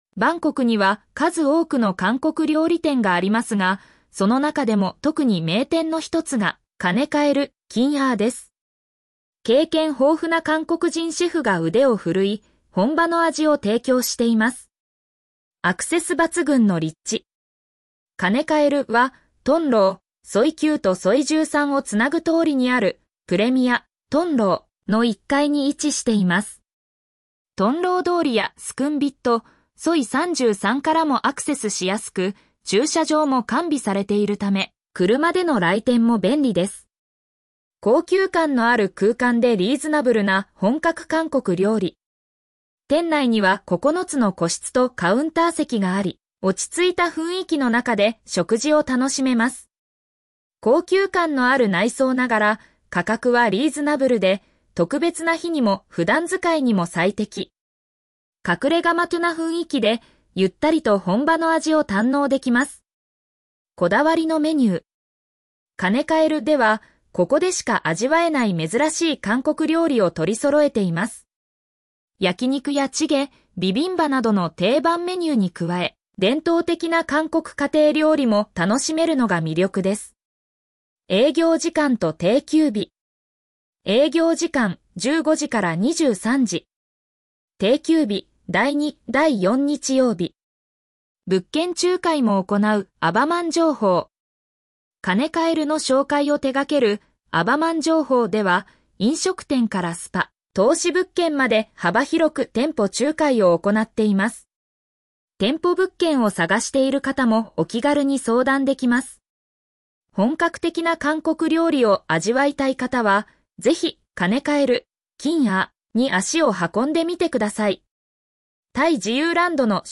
読み上げ